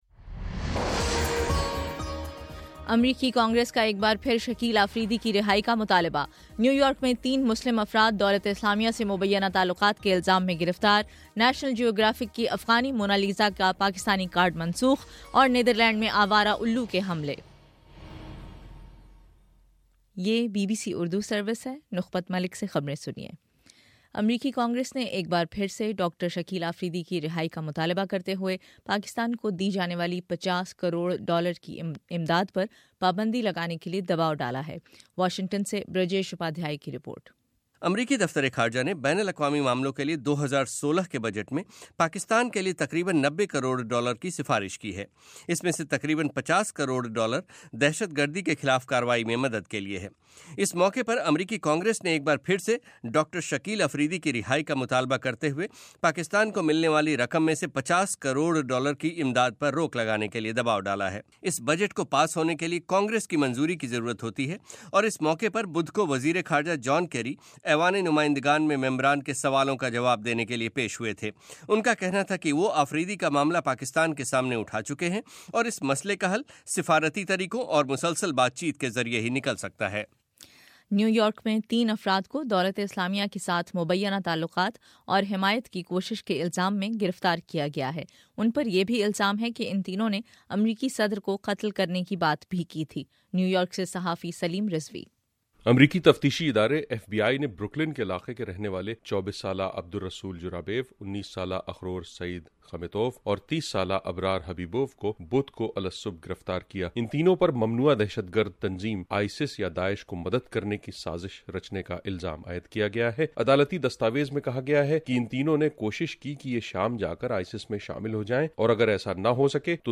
فروری 26 : صبح نو بجے کا نیوز بُلیٹن